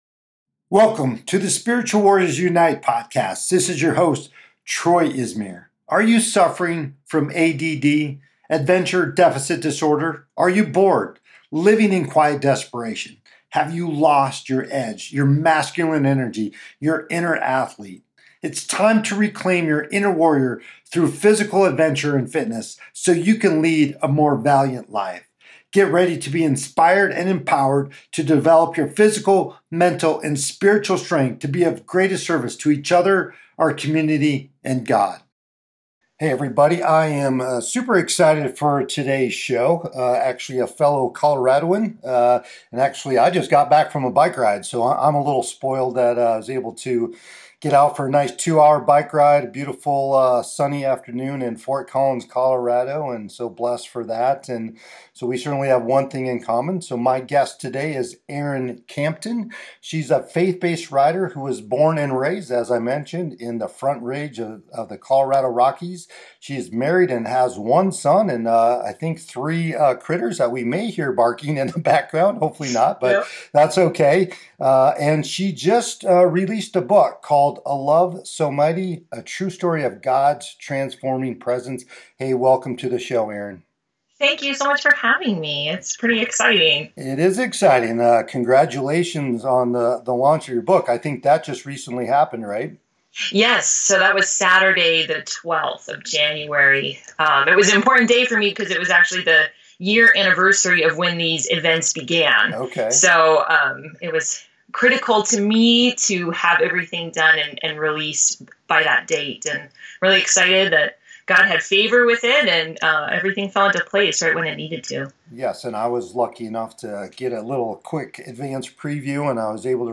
In this episode of the Spiritual Warriors Unite podcast I interview